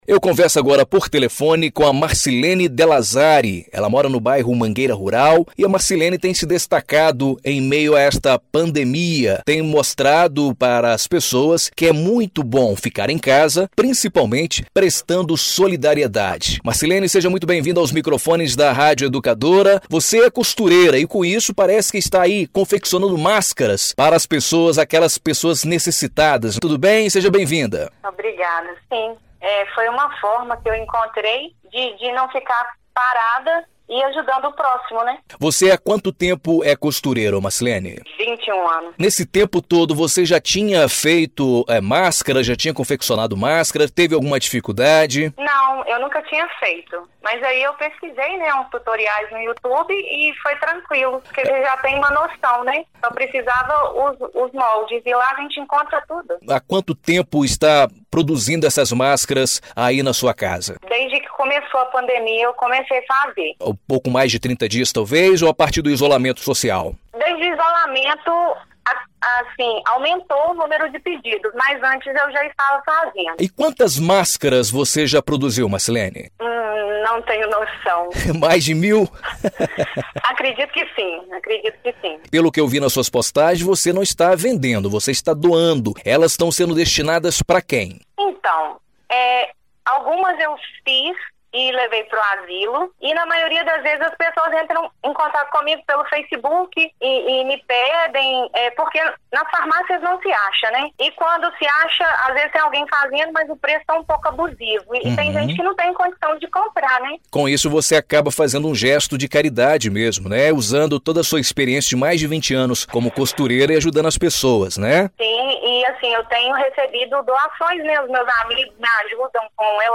Entrevista exibido na Rádio Educadora AM/FM Ubá-MG